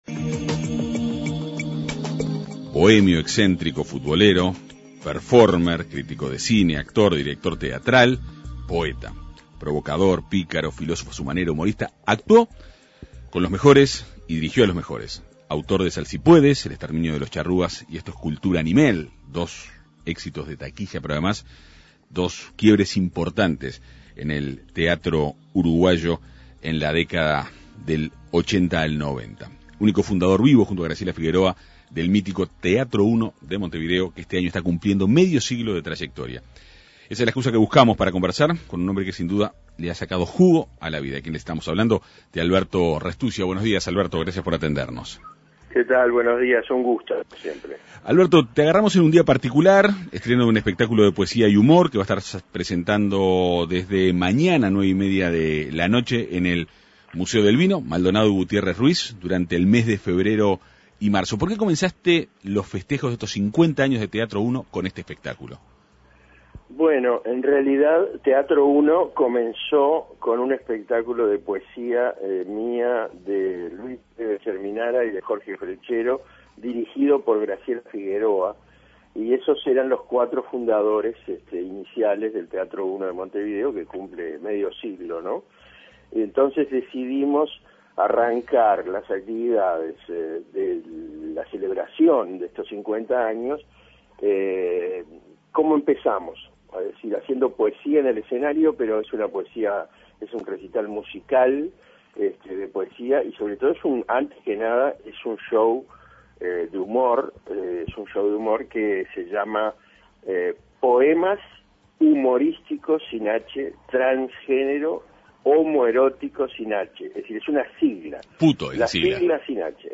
Alberto Restuccia conversó en la Segunda Mañana de En Perspectiva.